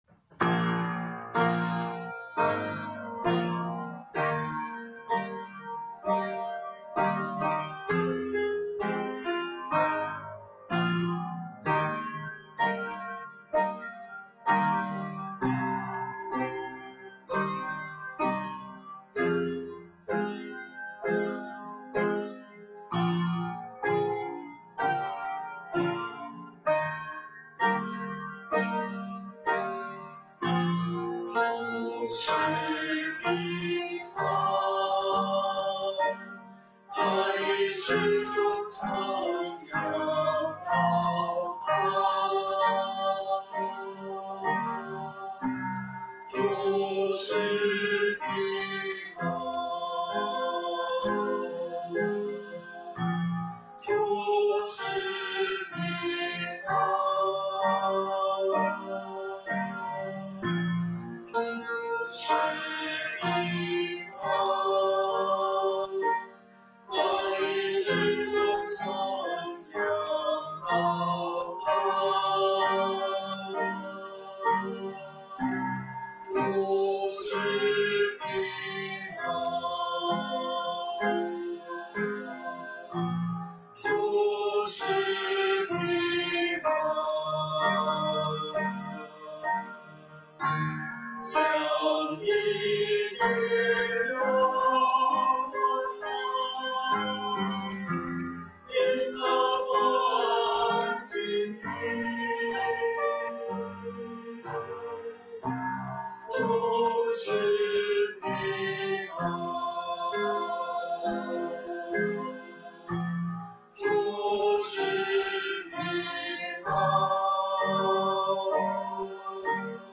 在约伯的天平上 | 北京基督教会海淀堂